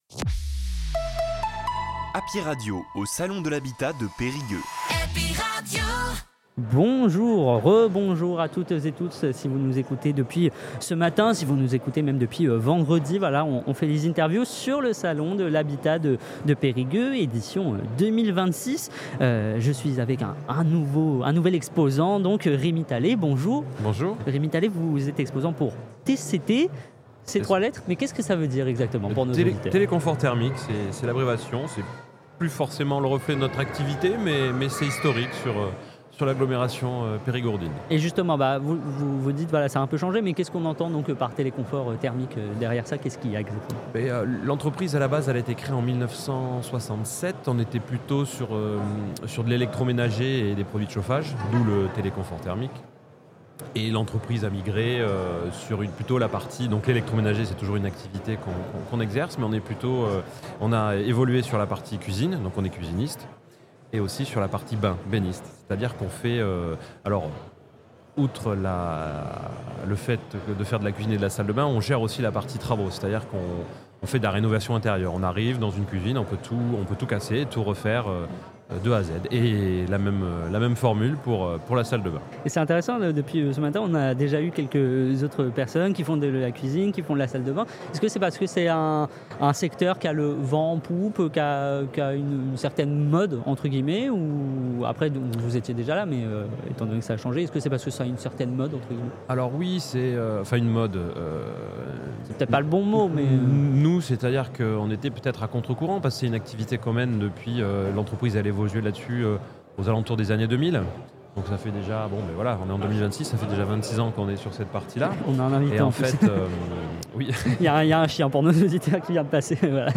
Salon habitat du périgord - Périgueux 2026